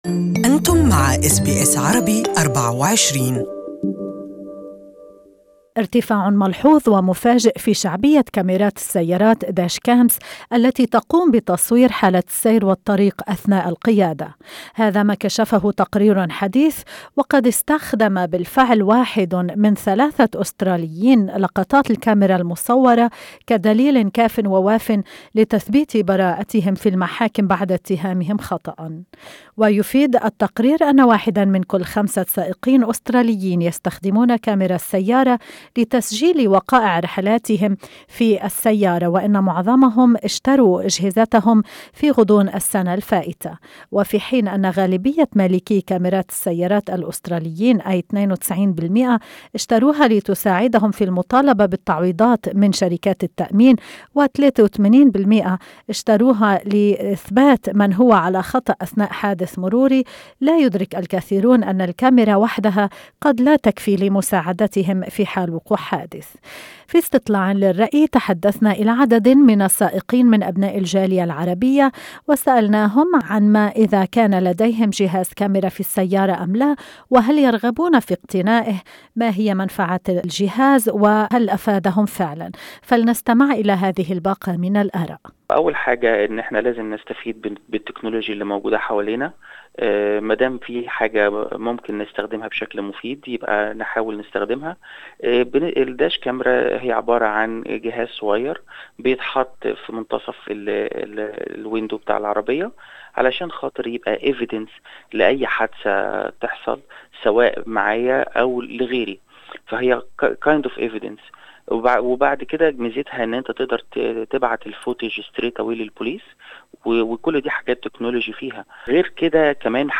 اقرأ المزيد احذروا القيادة بسرعة أقل من السرعة المحددة فالغرامة قد تكون باهظة عن مهام وفعالية كاميرا السيارات، سُئل عدد من السائقين من أبناء الجالية العربية في أستراليا اذا كان لديهم جهاز كاميرا في السيارة أم لا، وهل يرغبون في اقتنائه؟